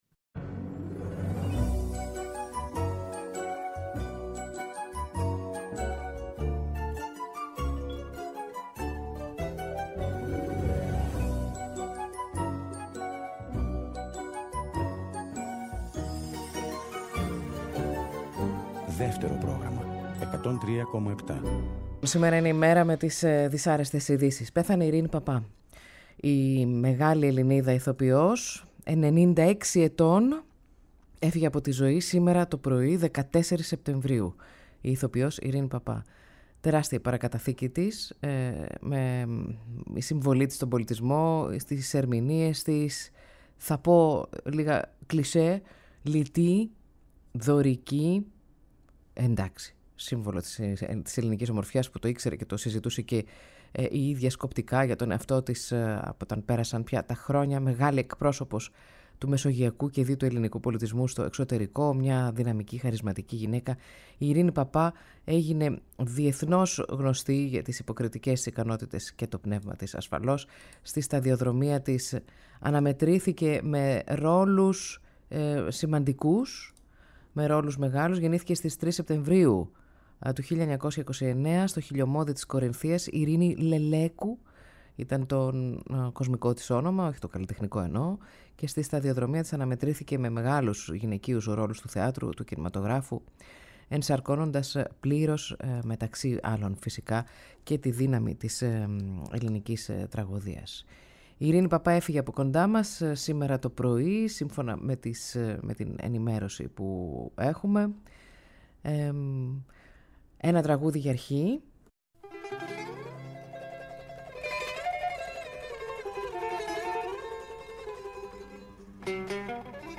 ΔΕΥΤΕΡΟ ΠΡΟΓΡΑΜΜΑ Transistor Podcast στο Δεύτερο Αφιερώματα Εκπομπές Ειρηνη Παπα